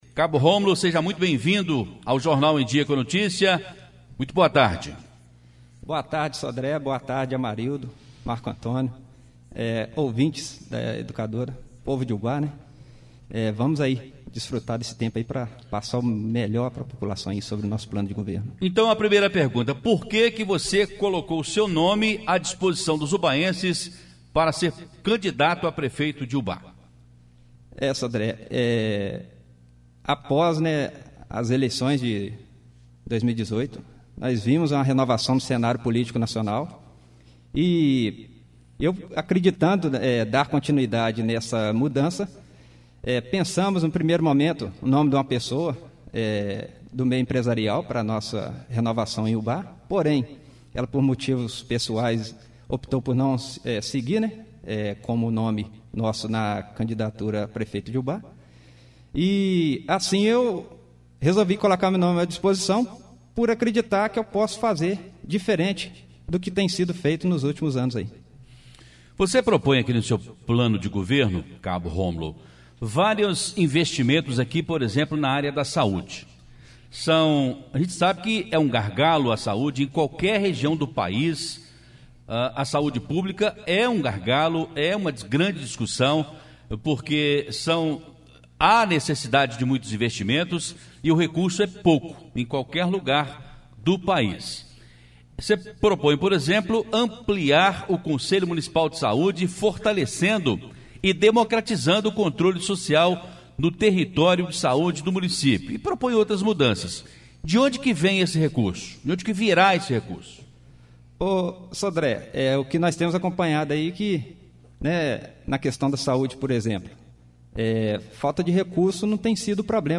Educadora na boca da urna!Entrevista às 12h30.
Entrevista exibida na Rádio Educadora AM/FM Ubá-MG